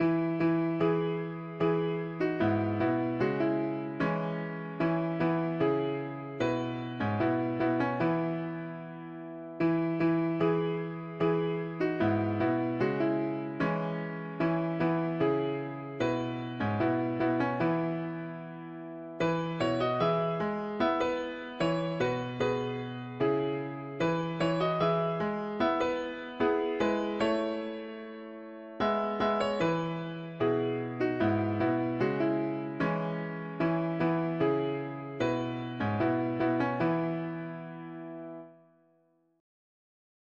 Jesus ready stands to save you, full of pity joined with pow’r: He is able, he … english christian 3part chords
2021 Key: E major Meter